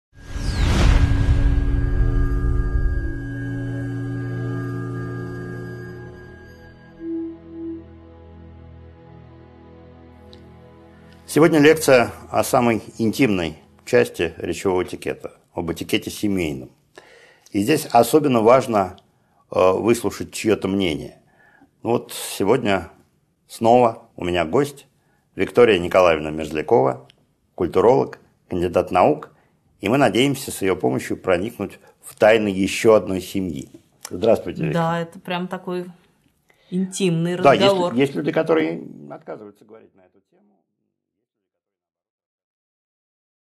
Аудиокнига 7.1 Диалог о семейном этикете | Библиотека аудиокниг
Прослушать и бесплатно скачать фрагмент аудиокниги